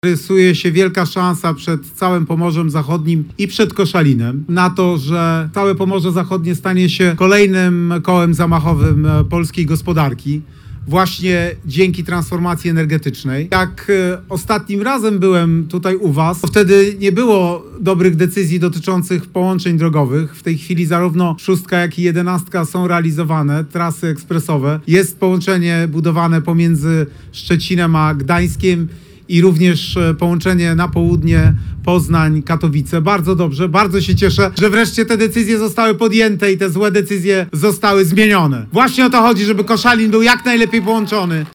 W piątek, 21 marca prezydent Warszawy i obecny kandydat na prezydenta Polski, Rafał Trzaskowski spotkał się z mieszkańcami Koszalina.